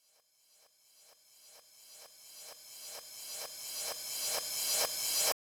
INT Crash Rev.wav